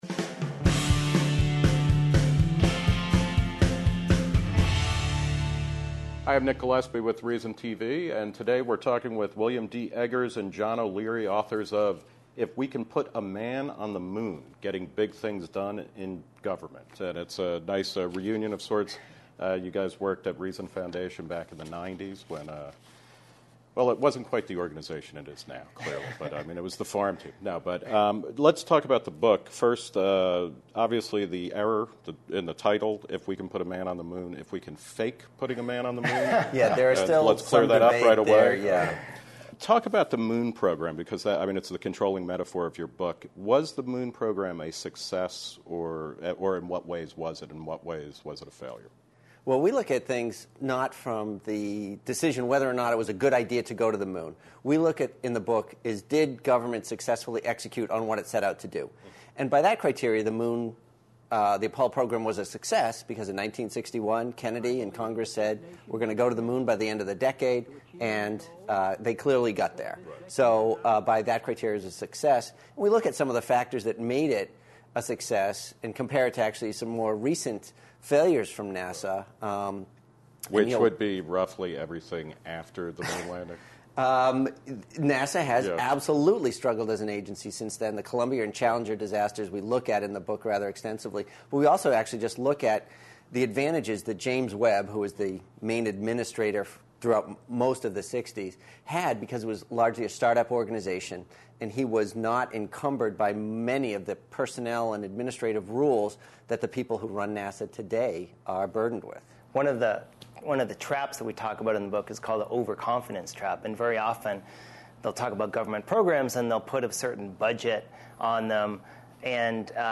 Interview by Nick Gillespie.